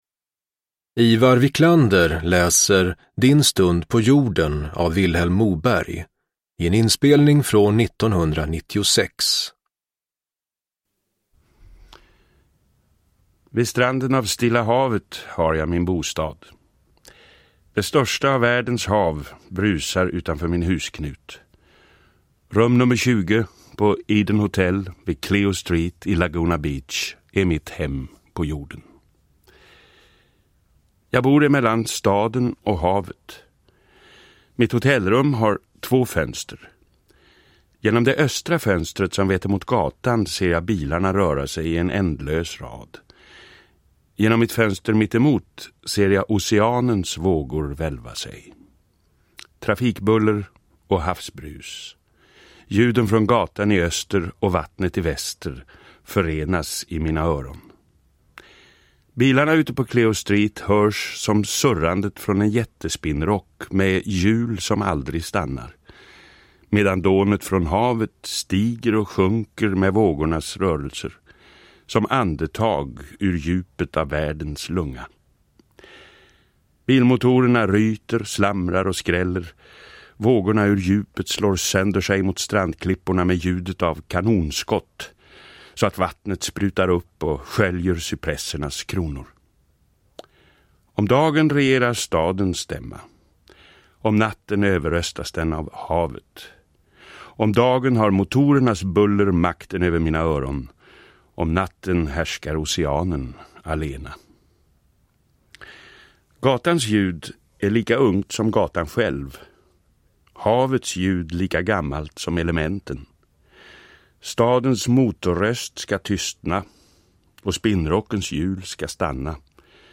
Uppläsare: Iwar Wiklander
Ljudbok
Iwar Wiklander läser Din stund på jorden i en inspelning för Sveriges Radio från 1996.